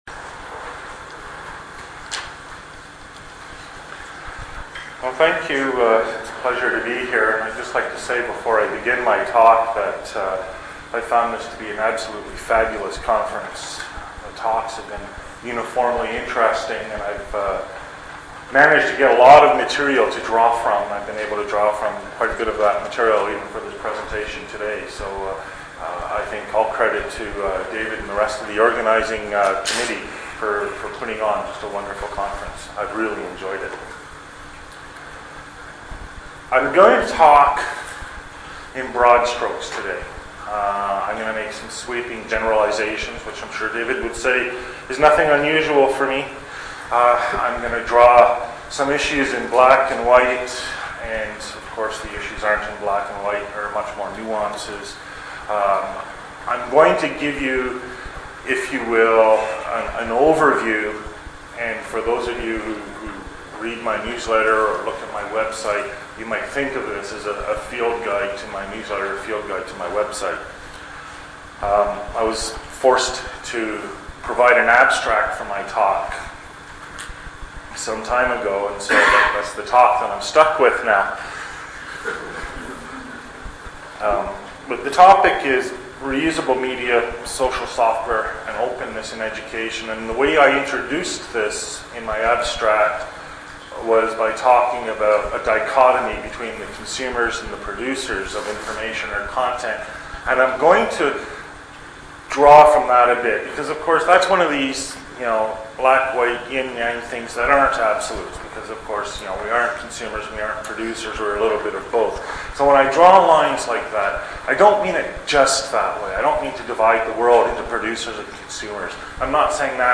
Instructional Technology Institute, Utah State University, Logan, Utah, Keynote, Sept 04, 2004.